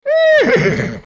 Horse #1 | TLIU Studios
Category: Animal Mood: Strong Editor's Choice